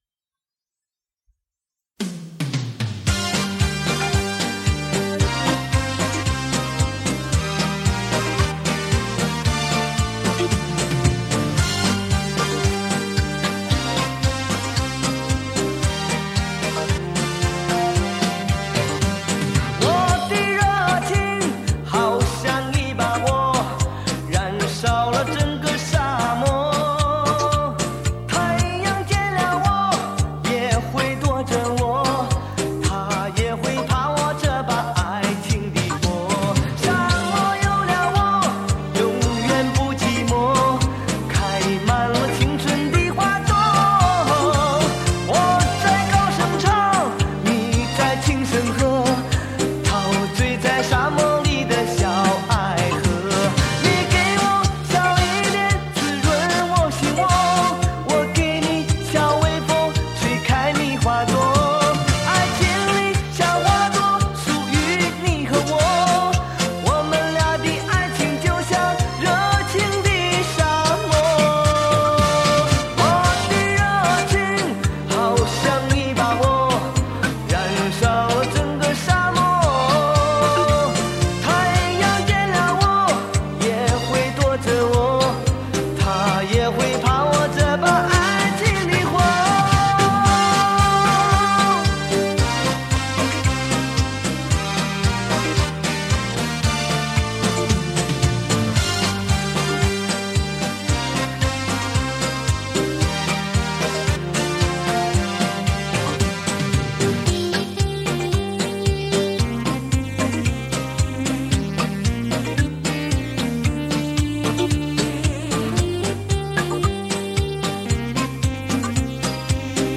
歌伴舞
快四